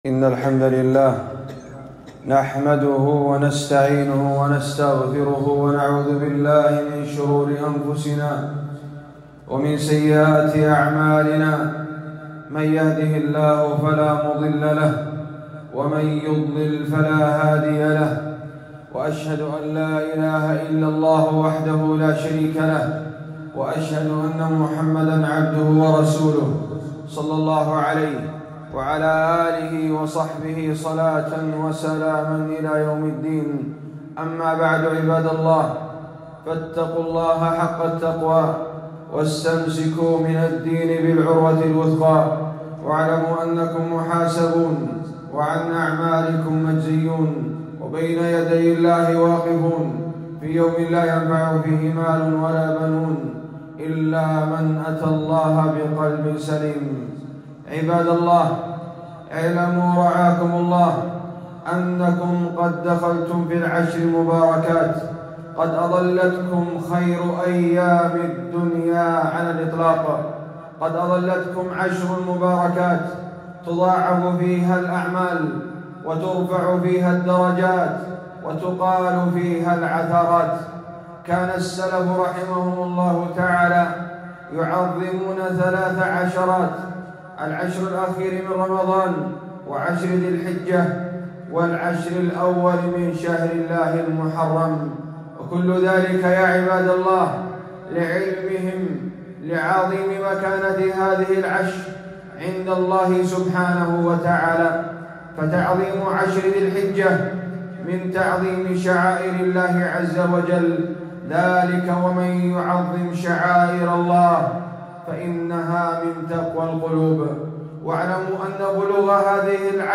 خطبة - خير أيام الدنيا